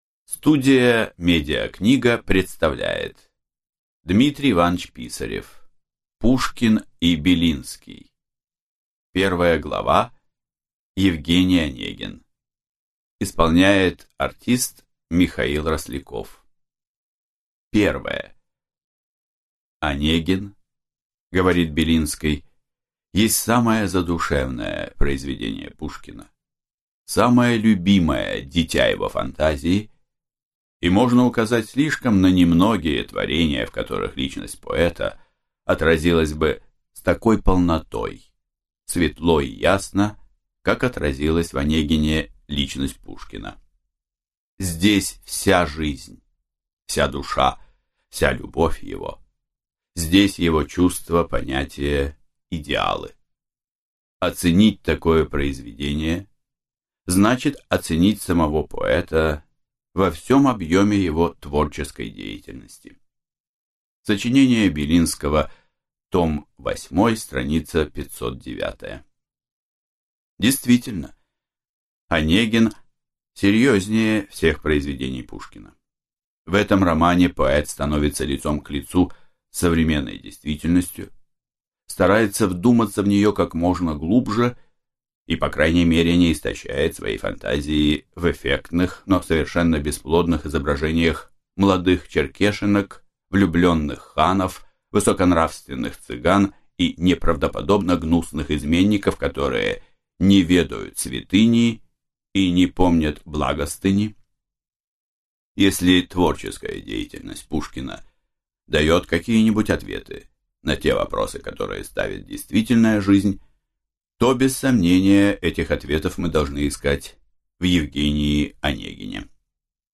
Аудиокнига Пушкин и Белинский (Евгений Онегин) | Библиотека аудиокниг